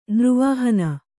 ♪ nř vāhana